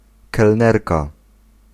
Ääntäminen
Ääntäminen France Tuntematon aksentti: IPA: /sɛʁ.vøz/ Haettu sana löytyi näillä lähdekielillä: ranska Käännös Ääninäyte 1. kelnerka {f} Suku: f . Serveuse on sanan serveur feminiinimuoto.